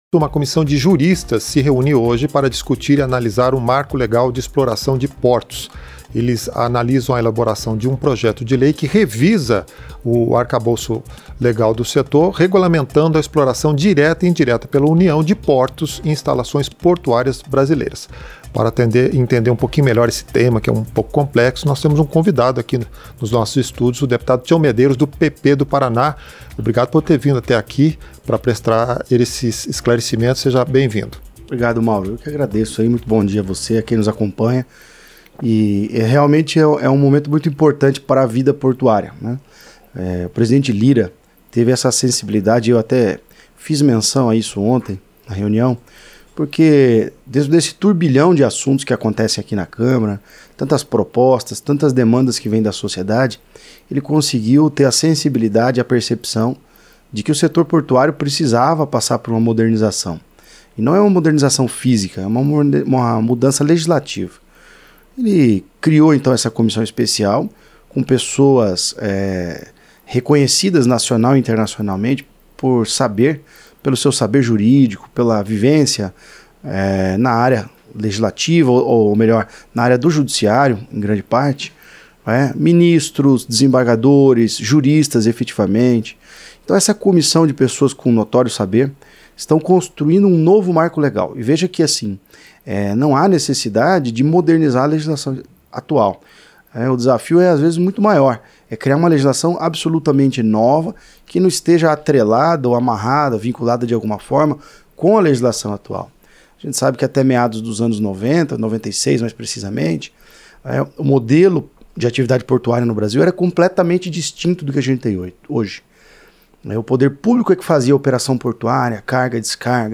Entrevista - Dep. Tião Medeiros (PP-PR)